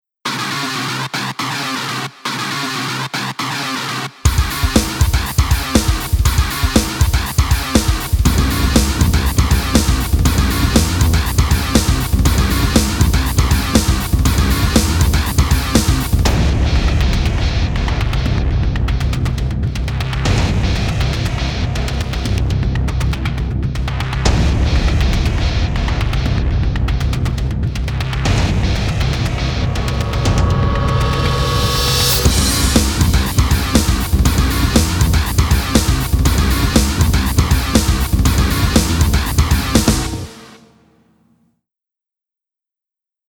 Rock & Pop